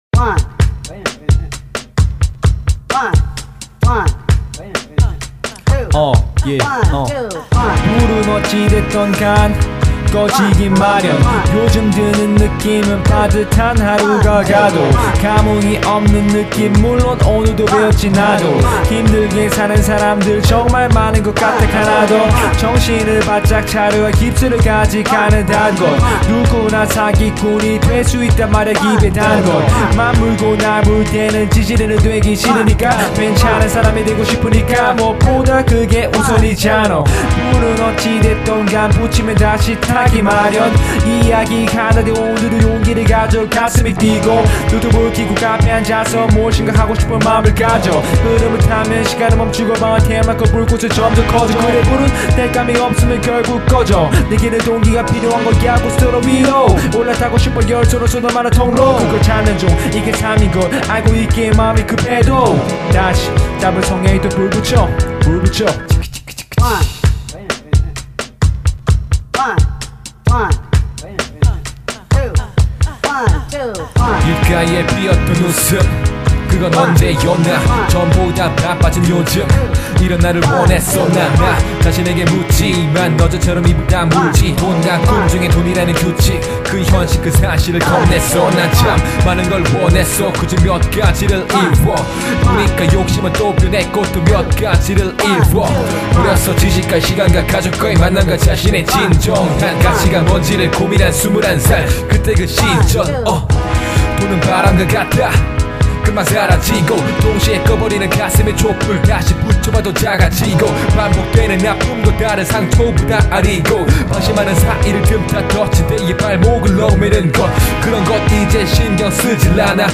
• [REMIX.]
두 사람이 녹음했으니 꼭 verse2까지 들어주세요